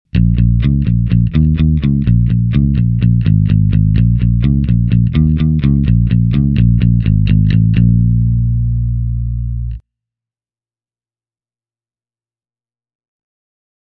Tältä basso kuulostaa soitettuna SansAmp Bass Driver DI:n läpi:
molemmat mikrofonit – plektra
lakland-skyline-44-60-both-pus-plectrum.mp3